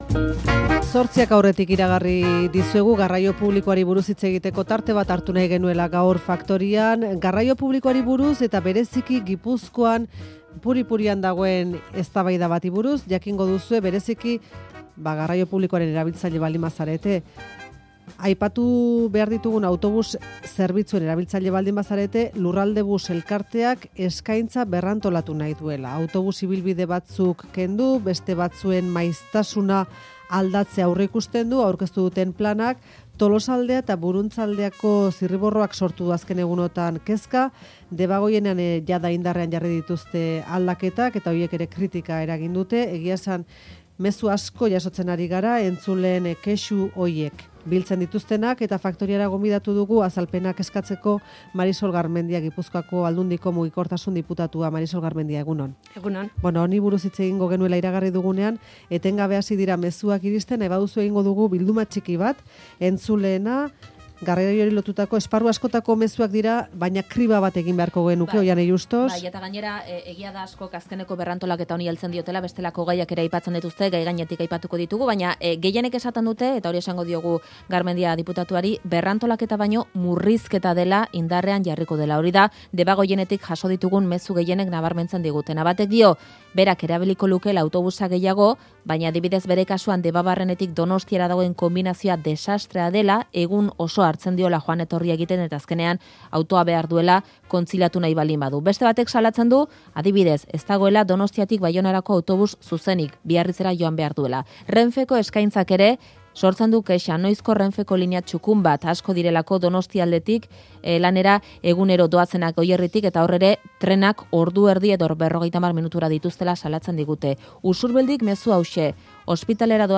Audioa: Gipuzkoako herri arteko autobus zerbitzua birmoldatzeko proposamenari buruzko elkarrizketa, Marisol Garmendia mugikortasun diputatuari.